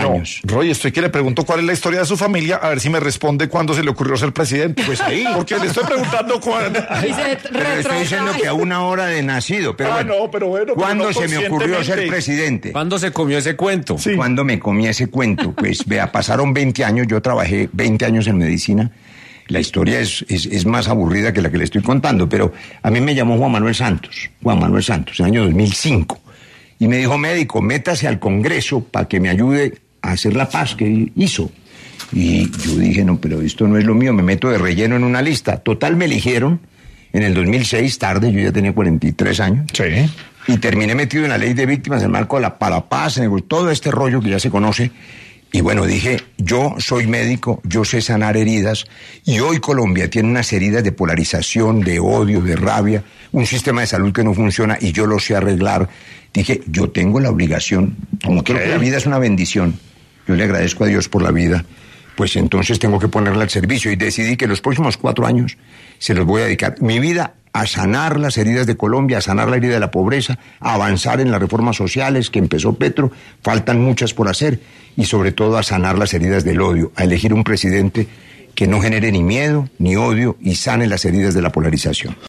Roy Barreras, candidato a la Presidencia, pasó por los micrófonos de Sin Anestesia de La Luciérnaga en Caracol Radio, para hablar sobre su candidatura.